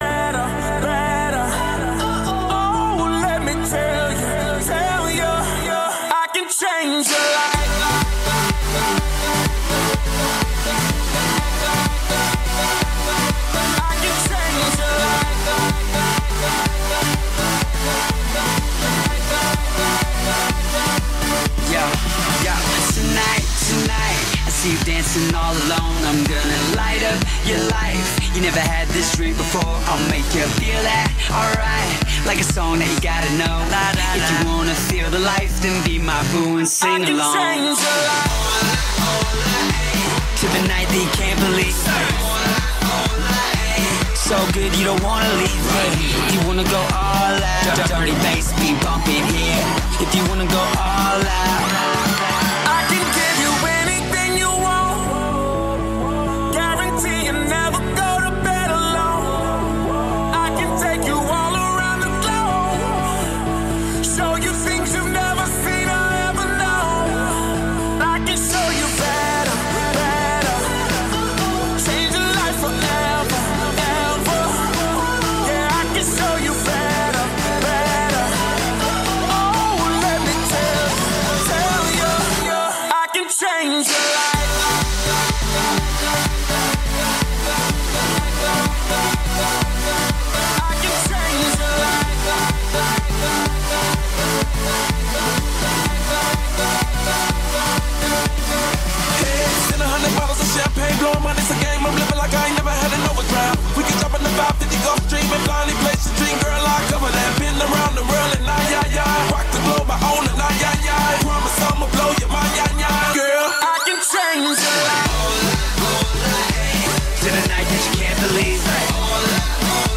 Live Wednesday through Saturday 9-1 with the Metro Best Karaoke with Mile High Karaoke on 06-Sep-25-20:44:23
Mile High Karaoke Live Wednesday through Saturday 9-1 Broadways Shot Spot